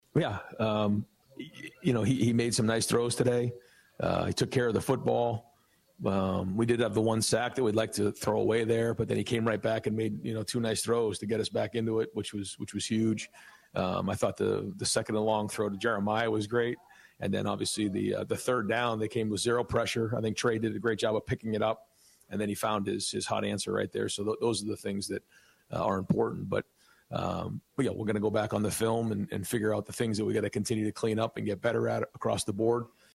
EXCERPTS FROM RYAN DAY’S POSTGAME PRESS CONFERENCE